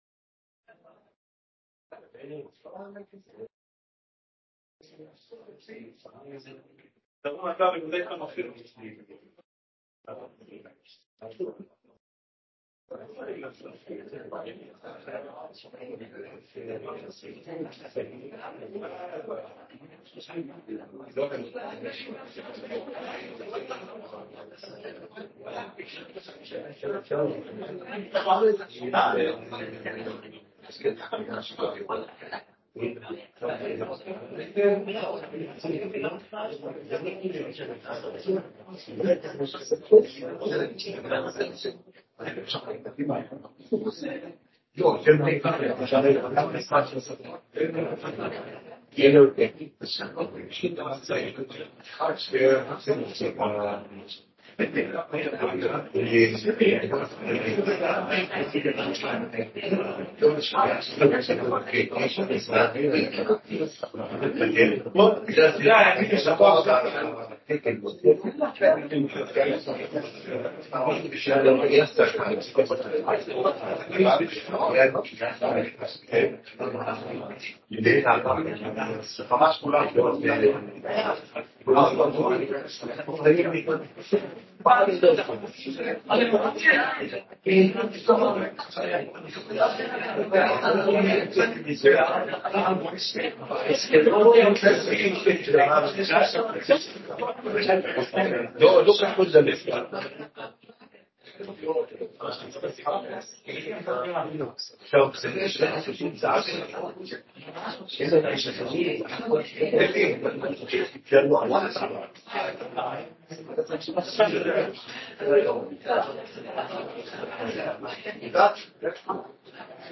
קטגוריה: ישיבת מליאה